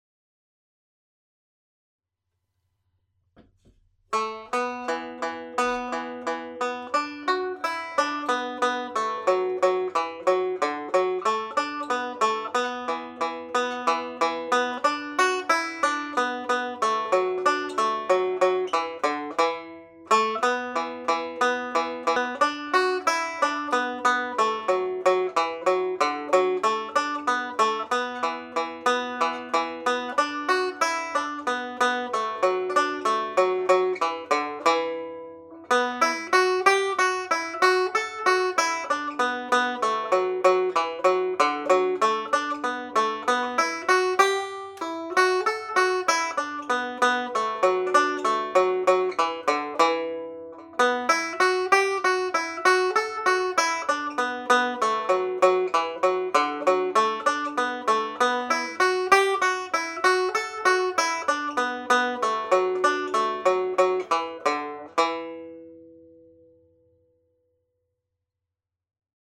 Pure Banjo » Videos
The Killavil Jig played slowly